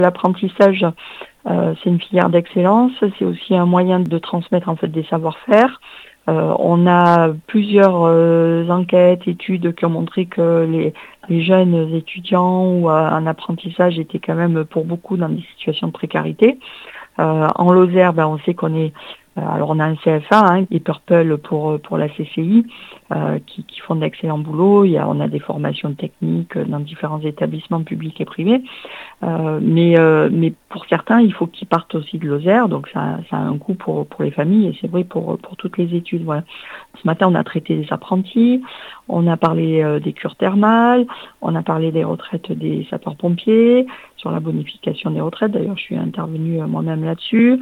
INTERVIEW. Budget de la sécu : Sophie Pantel votera pour